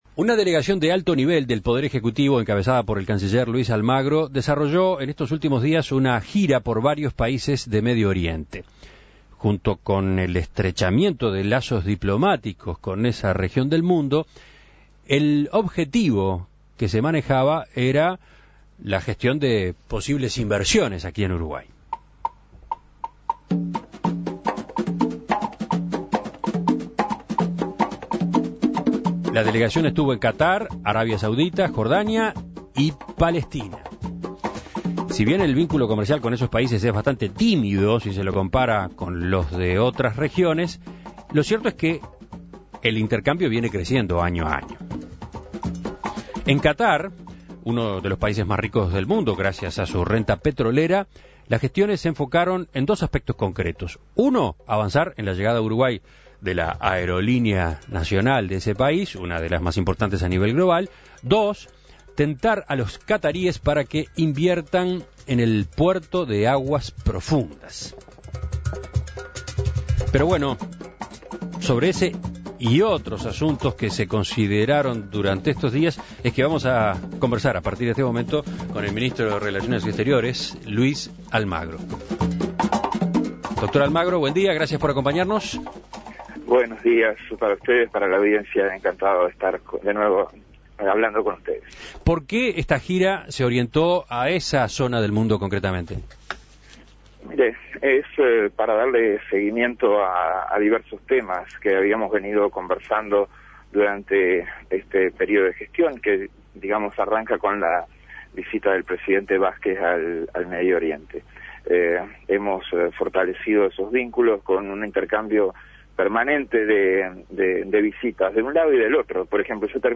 Una de las gestiones que se realizó fue la promoción de inversiones qataríes en el puerto de aguas profundas en Rocha. El canciller Luis Almagro, quien encabezó la comisión, dialogó con En Perspectiva sobre este viaje.